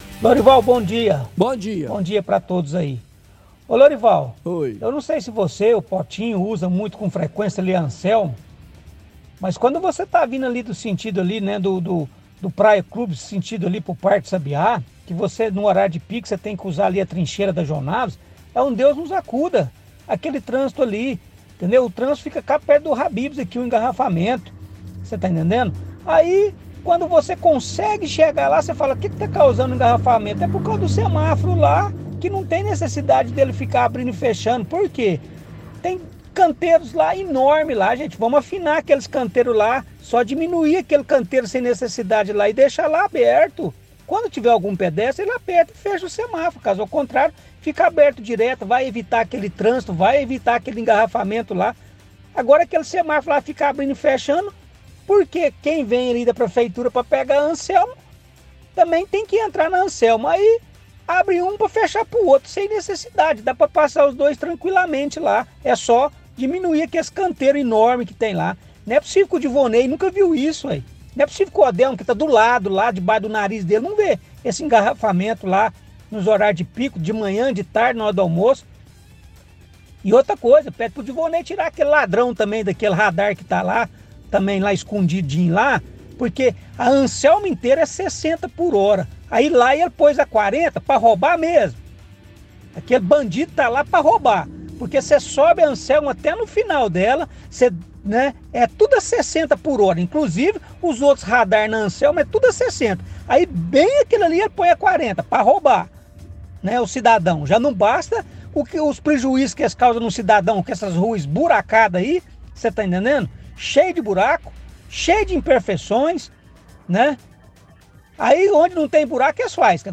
– Ouvinte reclama de trânsito em trincheira na Anselmo Alves dos Santos.